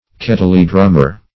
Kettledrummer \Ket"tle*drum`mer\, n. One who plays on a kettledrum.